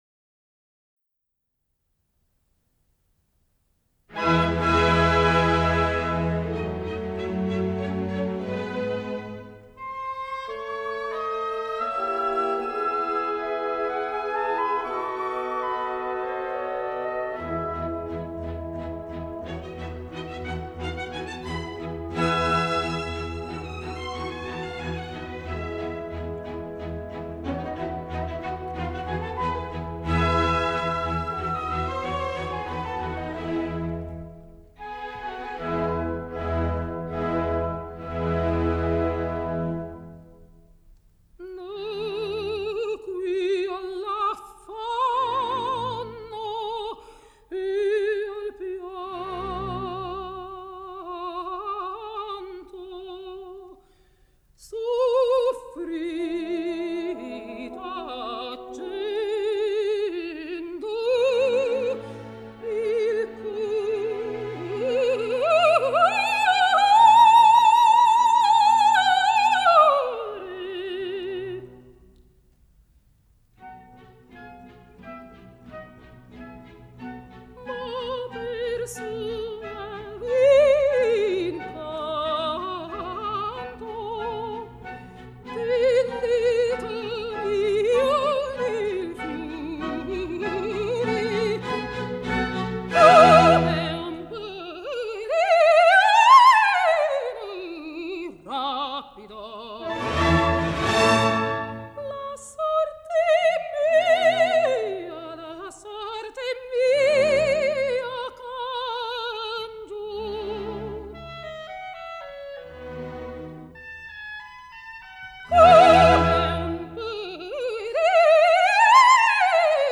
Жанр: Opera